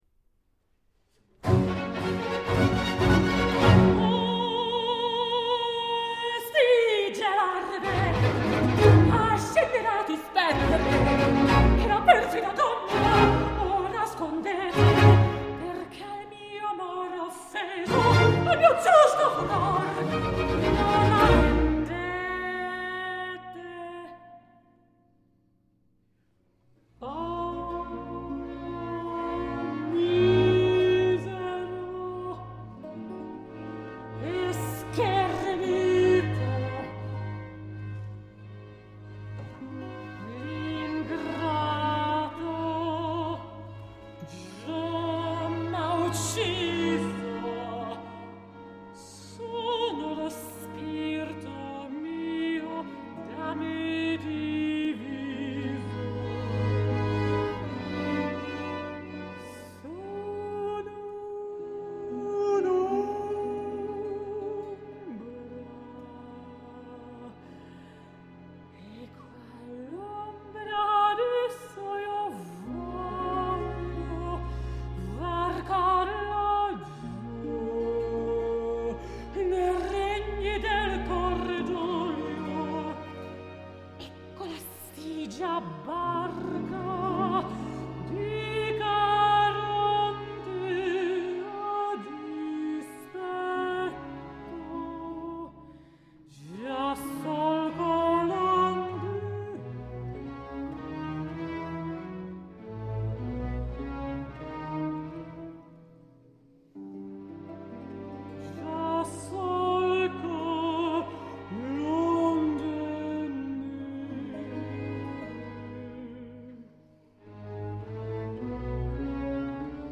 女 高 音